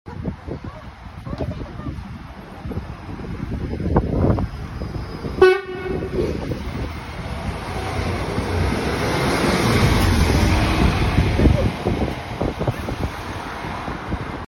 *Pride bus + sound driver* sound effects free download